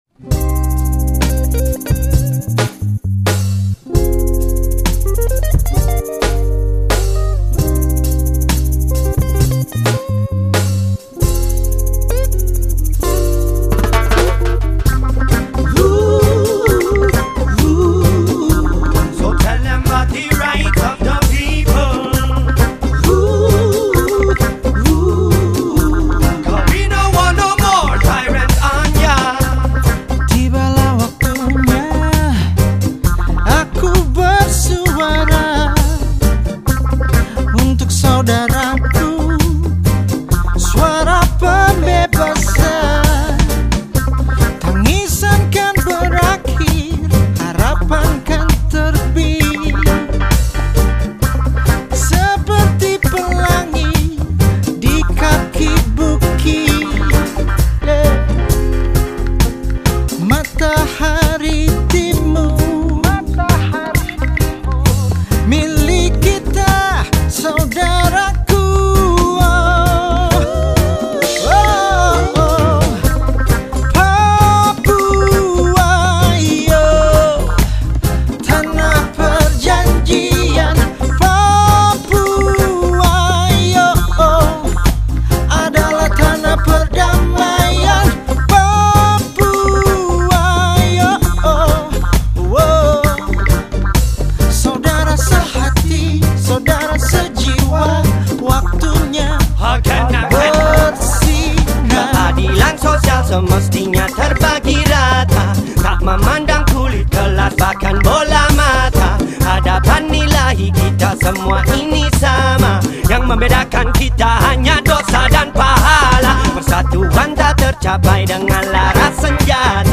Penyanyi pop dan R&B
musisi reggae